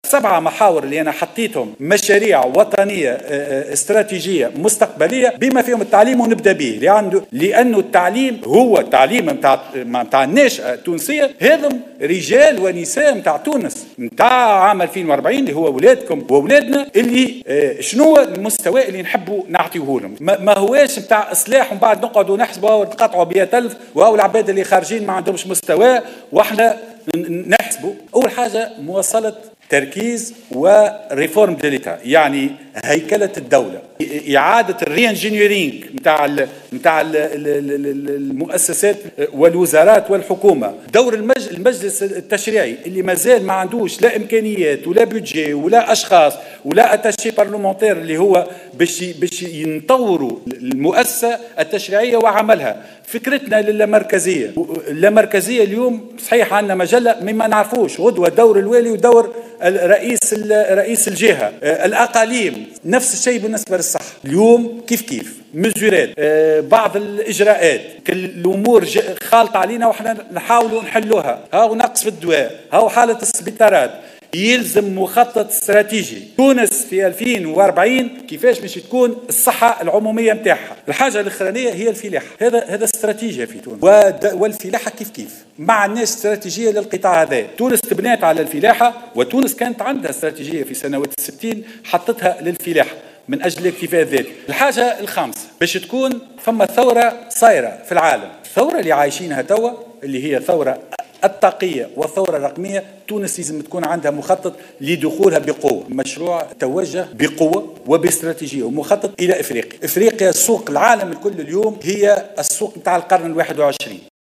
وأضاف اليوم خلال الندوة الصحفية التي عقدها لعرض آخر مستجدات مشاورات تشكيل الحكومة، أن أوّل أولوية هي إصلاح التعليم، ثم مواصلة هيكلة الدولة وتفادي النقائص في قطاع الصحة العمومية، إضافة الى الاهتمام بالقطاع الفلاحي وتثمينه والعمل على إحداث ثورة رقمية وطاقية، ووضع استراتيجية واضحة لدخول السوق الإفريقية.